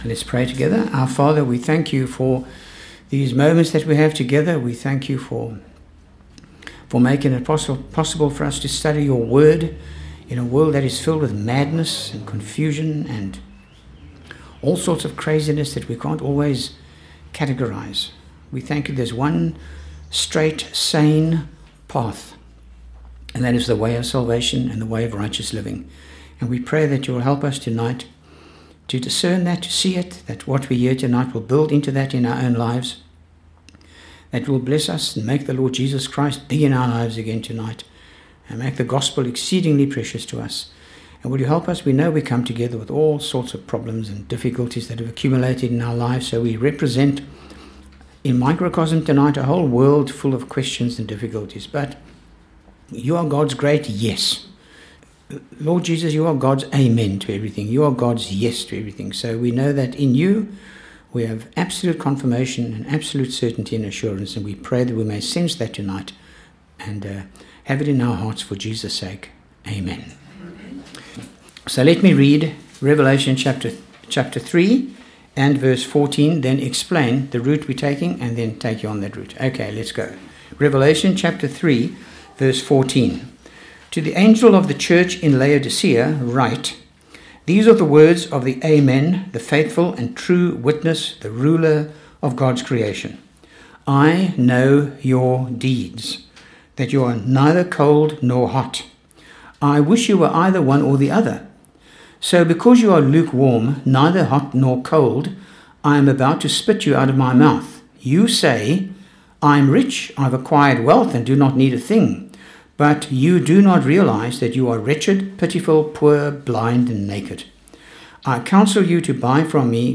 Revelation Talk 7